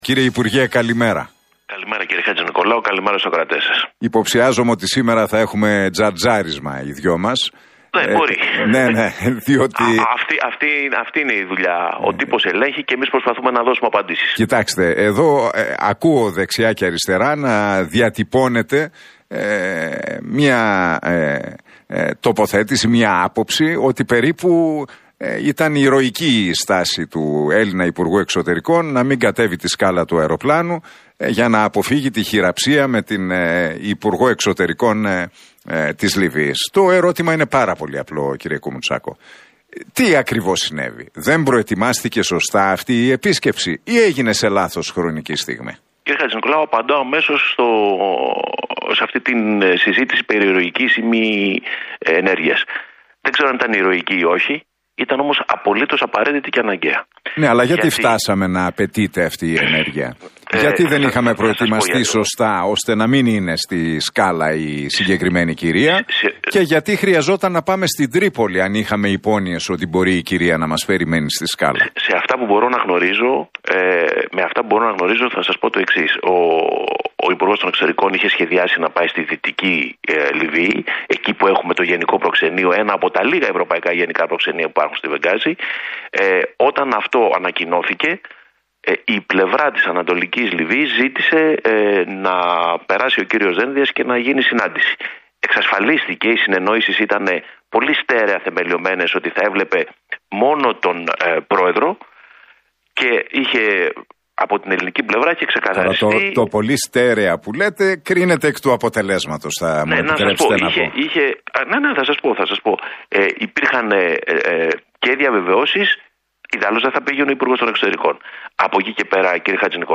Ο Γιώργος Κουμουτσάκος σε συνέντευξή του στον Realfm 97,8 και στον Νίκο Χατζηνικολάου σχολίασε πως «απαντώ στα περί ηρωικής ή μη ενέργειας, δεν ξέρω αν ήταν ηρωική ή όχι, ήταν όμως απαραίτητη και αναγκαία» αναφορικά με το επεισόδιο με τον Νίκο Δένδια στην Λιβύη.